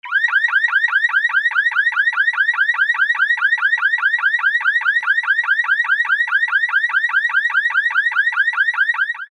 громкие
Сирена
автомобили
Звук сигнализации авто